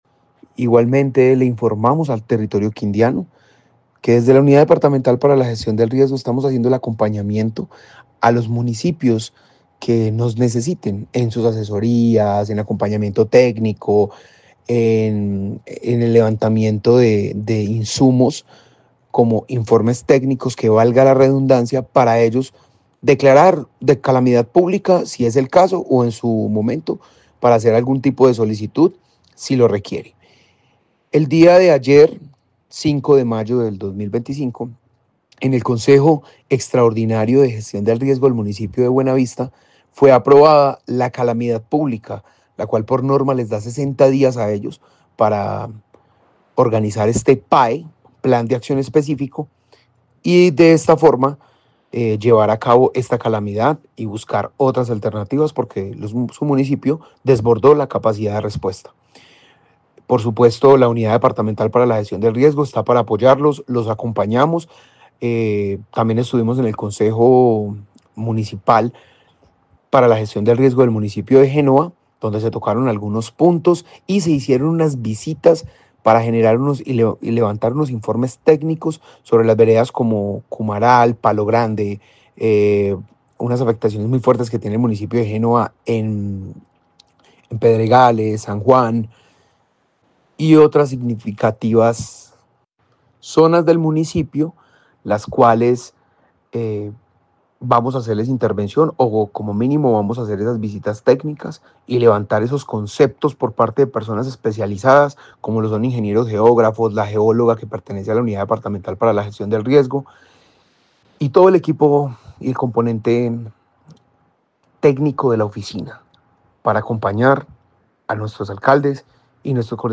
Jaider Higaldo, director gestión riesgo Quindío